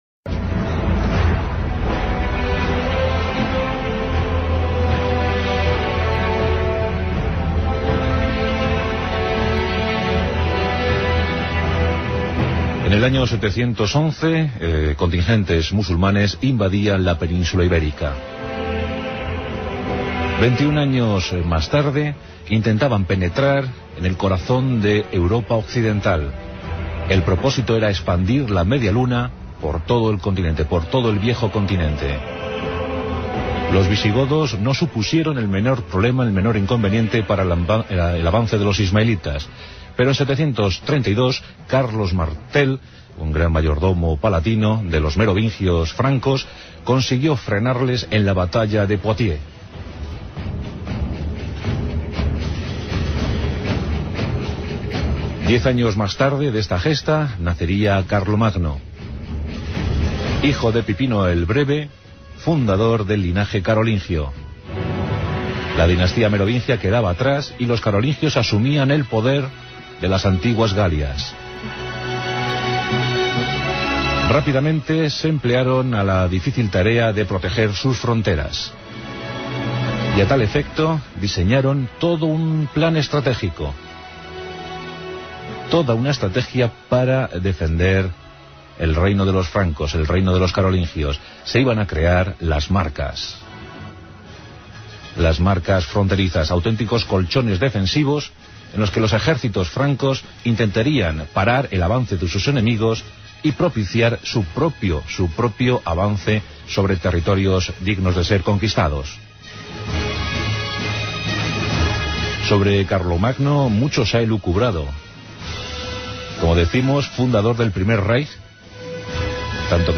En este Pasaje, Juan Antonio Cebrián, narra la expansión musulmana en la península ibérica y la posterior reacción de los carolingios para detener su avance. Carlos Martel logró frenar a los musulmanes en la batalla de Poitiers en el año 732.